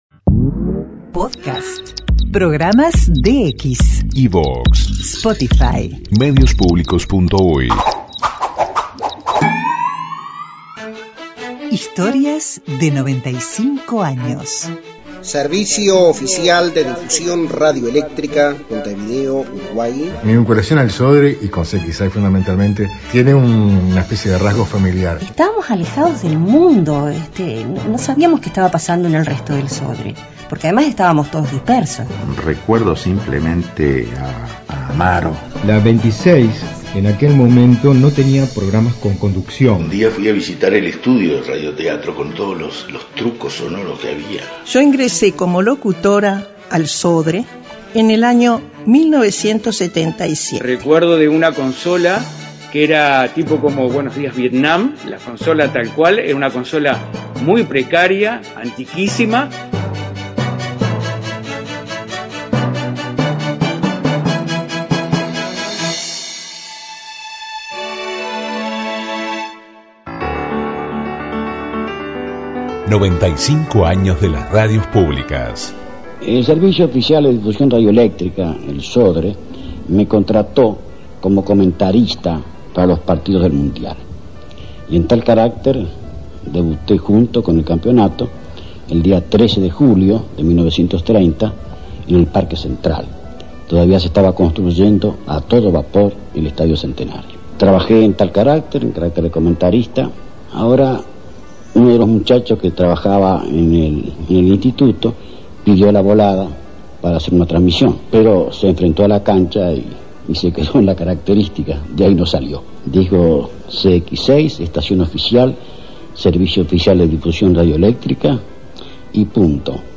Edición especial en Radio Splendid 990